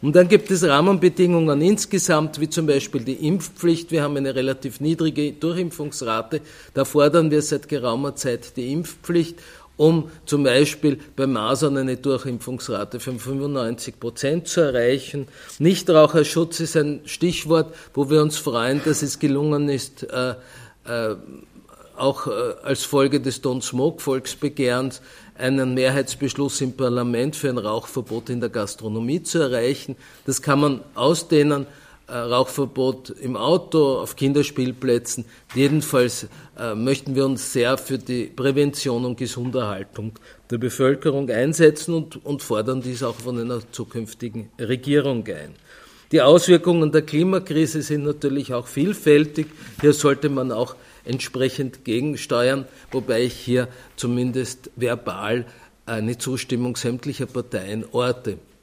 O-Töne (MP3)